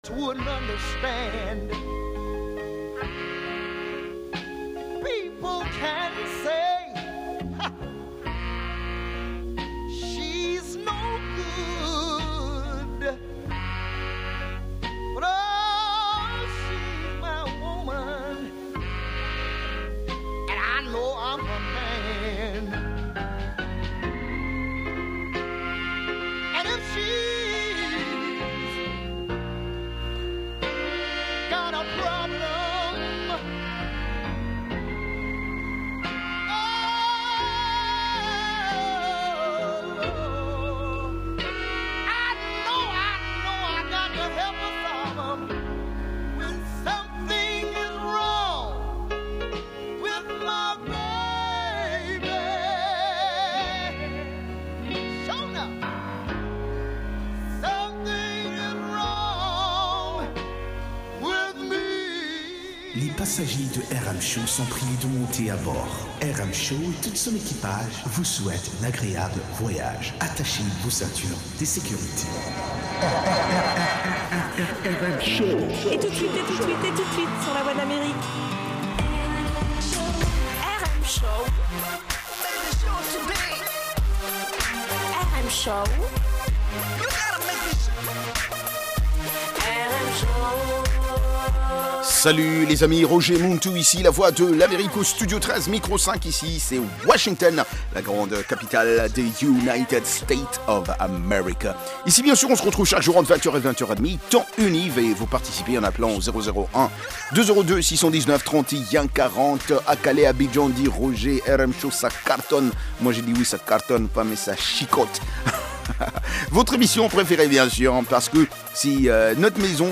Blues and Jazz Program